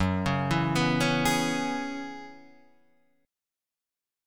F#9sus4 chord